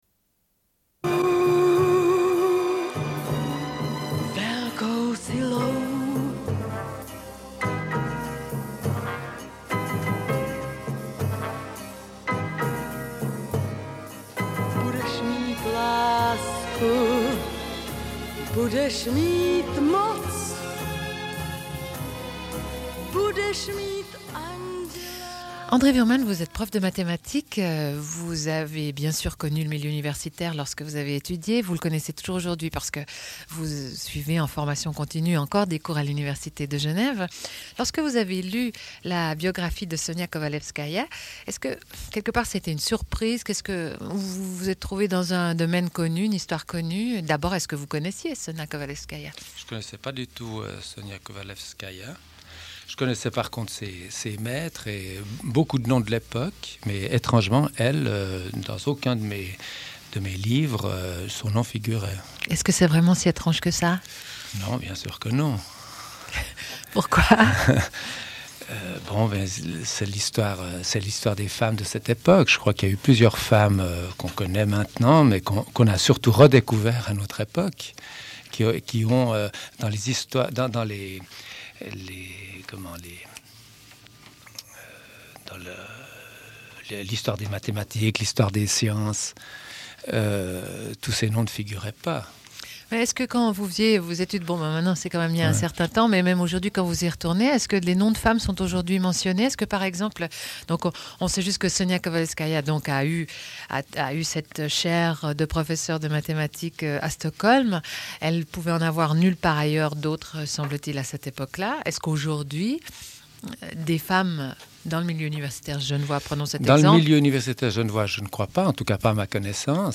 Une cassette audio, face B29:13